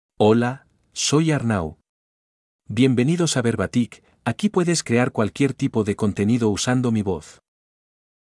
MaleSpanish (Spain)
ArnauMale Spanish AI voice
Arnau is a male AI voice for Spanish (Spain).
Voice sample
Arnau delivers clear pronunciation with authentic Spain Spanish intonation, making your content sound professionally produced.